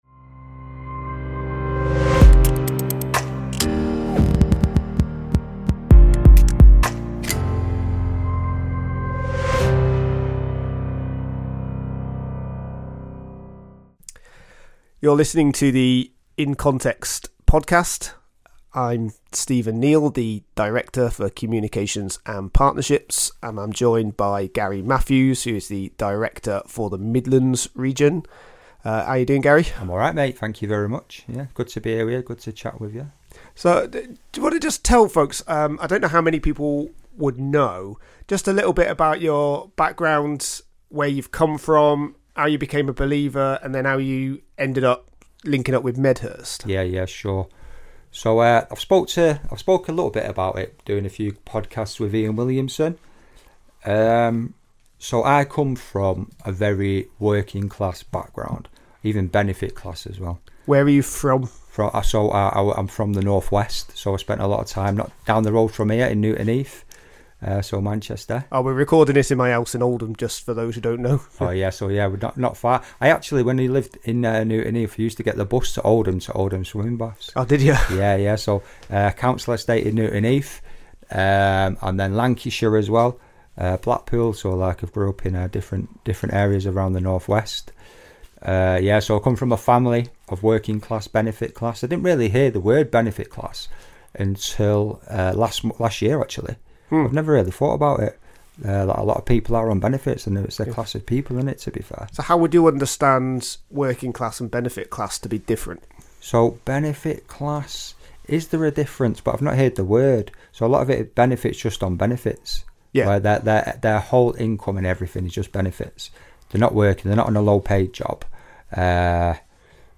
sit down for a candid conversation about the 'why' behind the work.